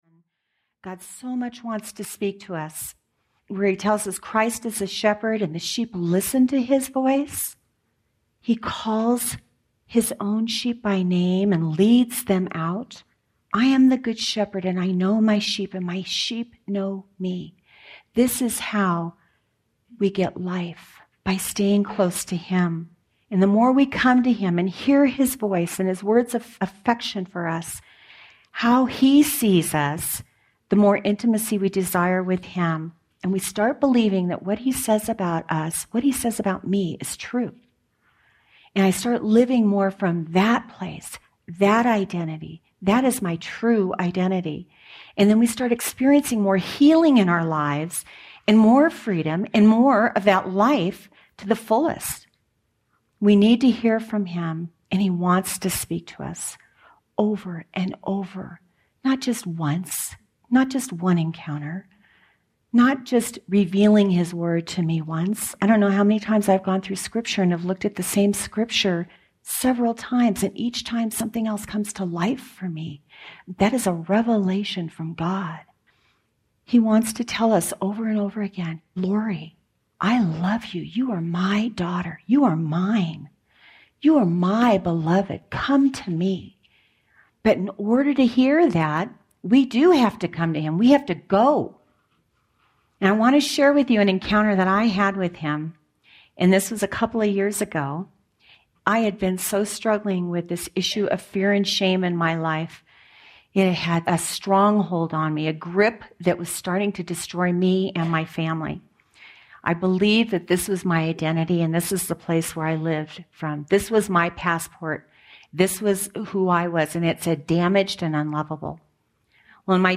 The Beloved Audiobook
Captivating Live Collection
1.1 Hrs. – Unabridged